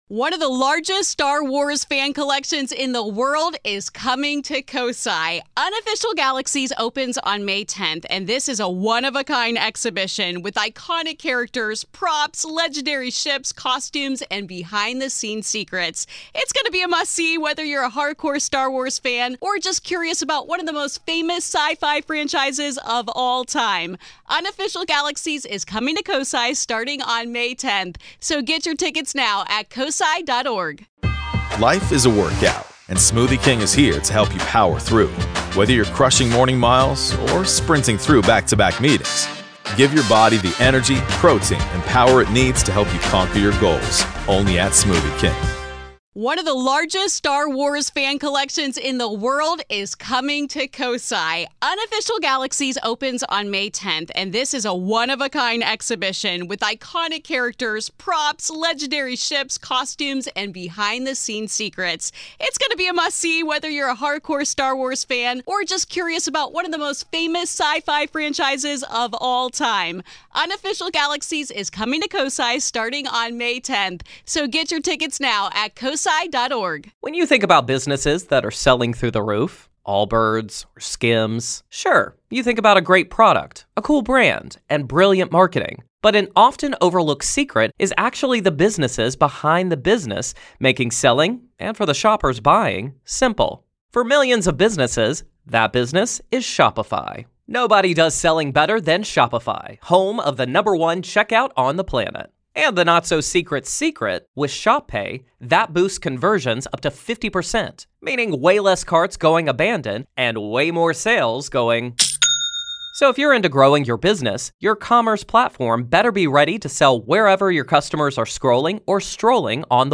One caller recounts her family’s chaotic life in a Phoenix home where anger and negativity may have opened the door to something monstrous.